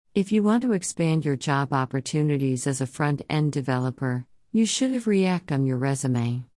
下記センテンスは、先輩エンジニア（この場合は女性）が後輩にReactを勧める文例です。
音声では、you should haveは”you should’ve”と変換されています。AWS テキスト読み上げ機能の「ニューラル」という変換エンジンによるものです。